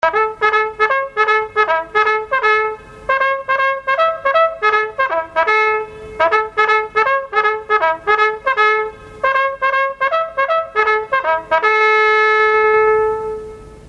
起床ラッパです。